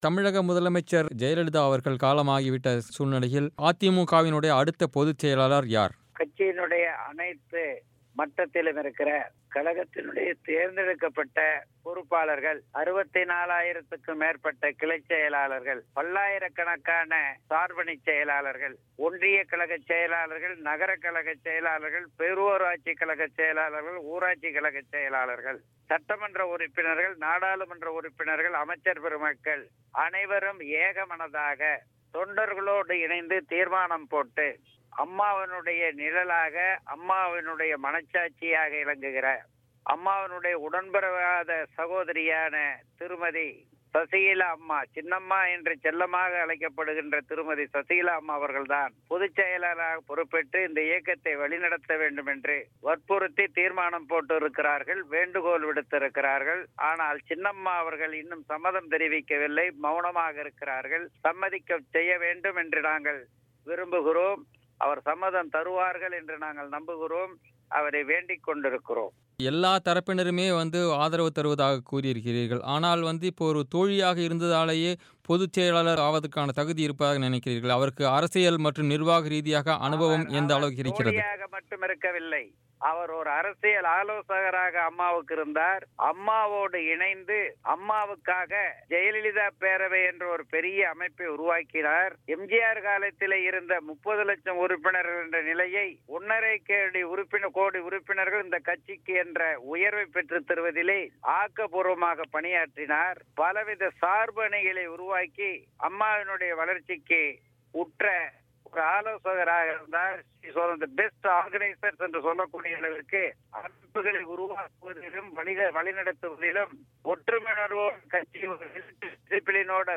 மறைந்த முதலமைச்சர் ஜெயலலிதாவுக்கு அளிக்கப்பட்ட சிகிச்சையின்போது நடந்த உண்மை என்ன என்பது குறித்து அதிமுக செய்தித் தொடர்பாளர் பொன்னையன் பிபிசி தமிழுக்கு பேட்டி